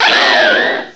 cry_not_braviary.aif